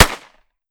9mm Micro Pistol - Gunshot B 004.wav